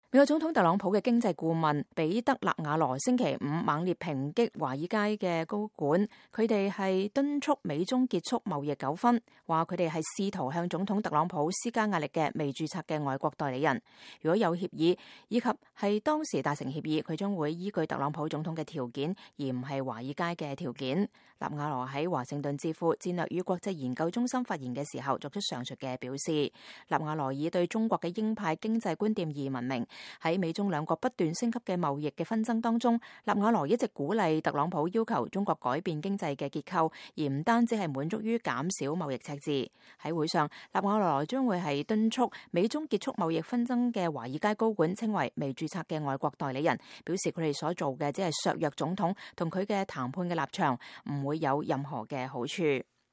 2018年11月9日，特朗普貿易顧問彼得·納瓦羅在戰略與國際研究中心發表講話。